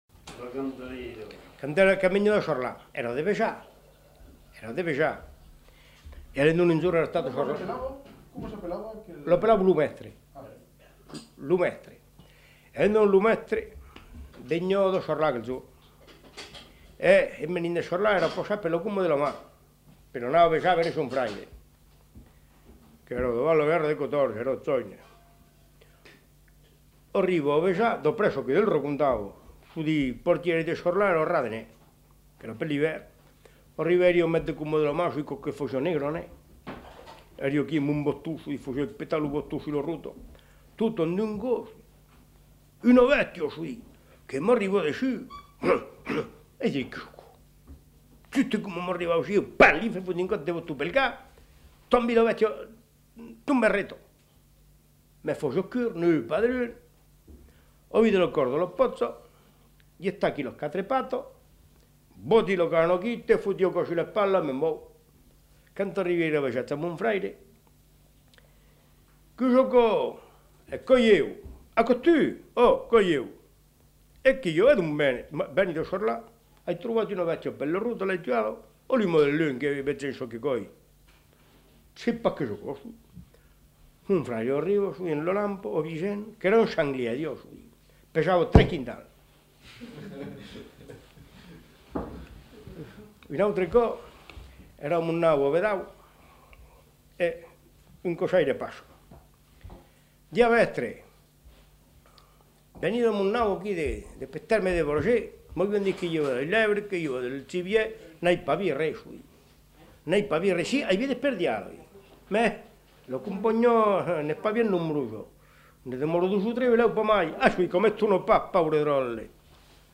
Aire culturelle : Périgord
Lieu : Daglan
Genre : conte-légende-récit
Type de voix : voix d'homme
Production du son : parlé